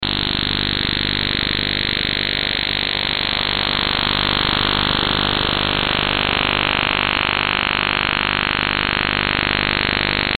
Quelli che trovate di seguito sono una serie di strani segnali HF digitali, soprattutto radar OTH, registrati con il ricevitore sdr Perseus usando un'antenna verticale a Ginevra nel mese di Febbraio 2009.
Segnale digitale multi sweep, ricevuto sui 10230 khz molto probabilmente un Radio OTHR.